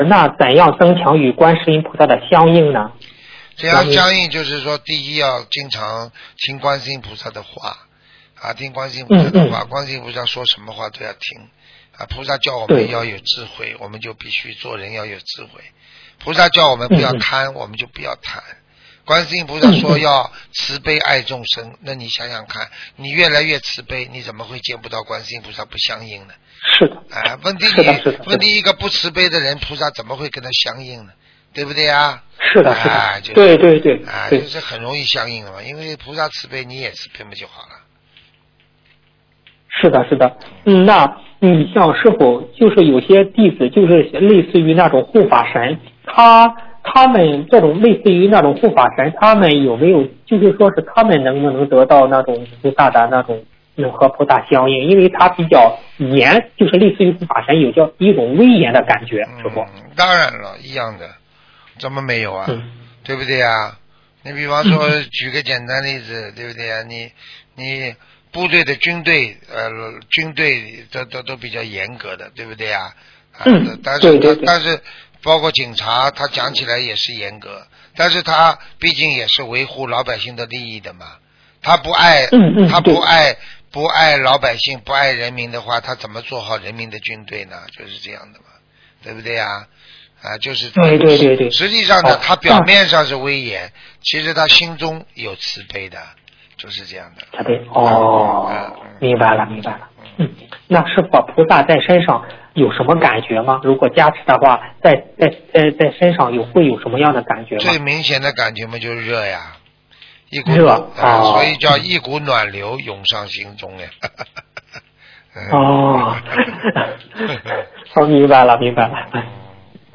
Tanya Jawab
Pendengar pria: Bagaimana cara meningkatkan keselarasan dengan Guan Shi Yin Pu Sa?